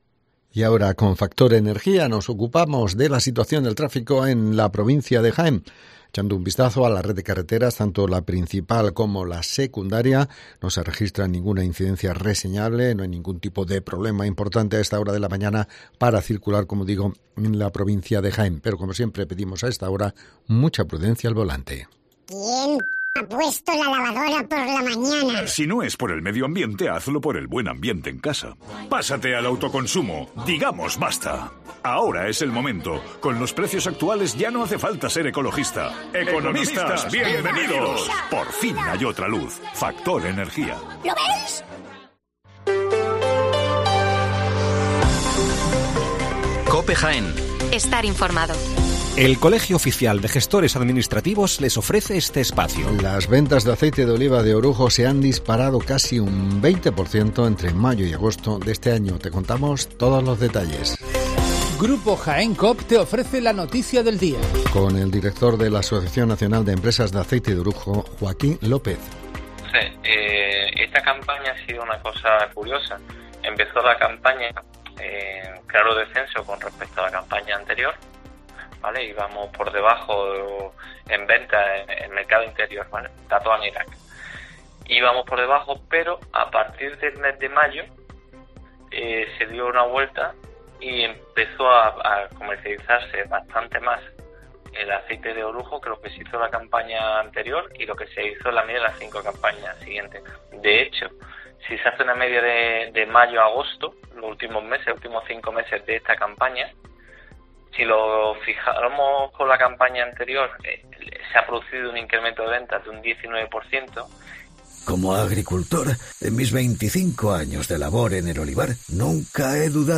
Las noticias locales de las 7'55 horas del 16 de octubre de 2023